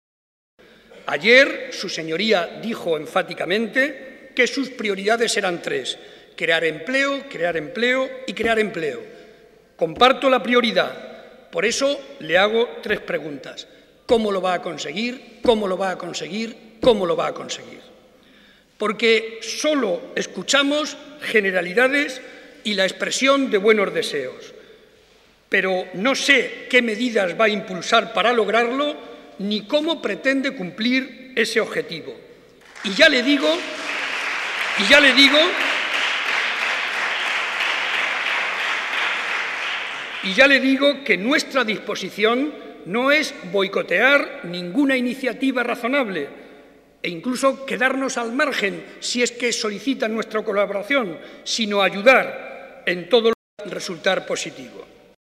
Cortes de audio de la rueda de prensa
audio_Barreda_Discurso_Debate_Investidura_210611_2